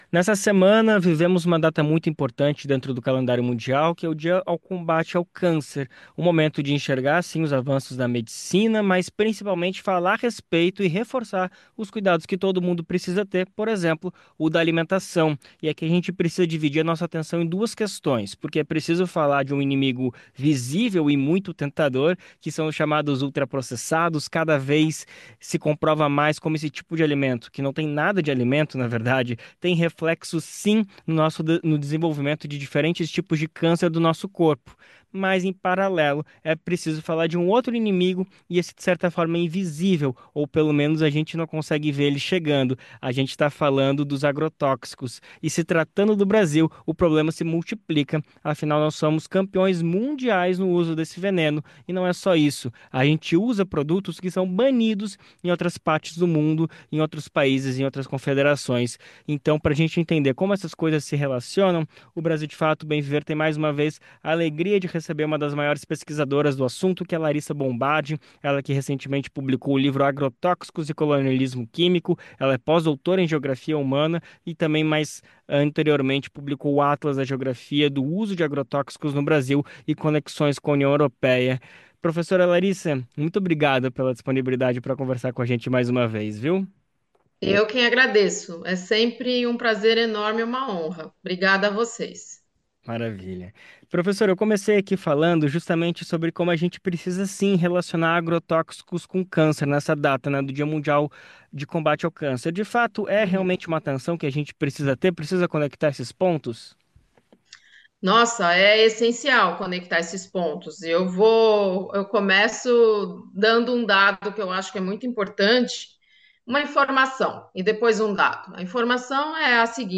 em entrevista ao programa Bem Viver desta quinta-feira (6).